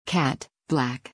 CAT /kæt/, BLACK /blæk/
発音のこつ 日本語の「ア」と「エ」の中間ぐらいの音です。
cat.mp3